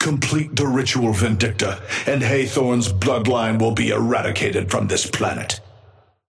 Amber Hand voice line - Complete the ritual, Vindicta, and Hathorne's bloodline will be eradicated from this planet.
Patron_male_ally_hornet_start_02.mp3